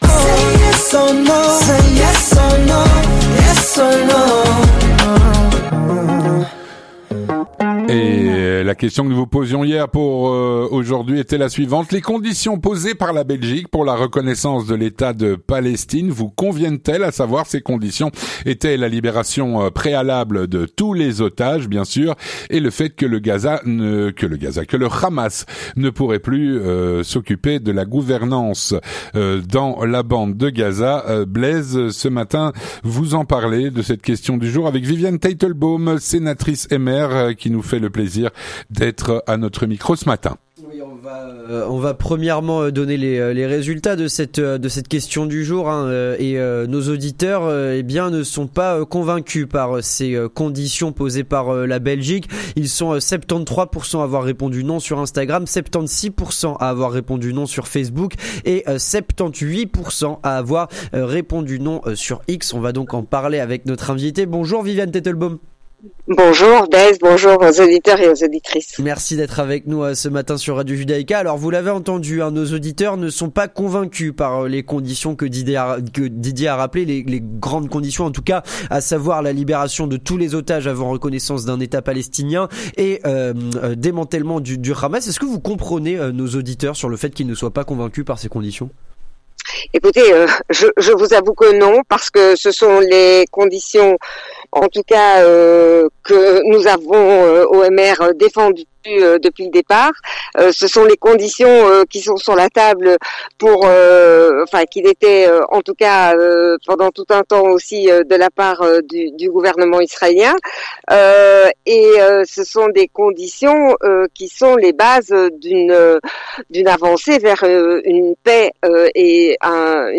Viviane Teitelbaum, sénatrice MR, répond à "La Question Du Jour".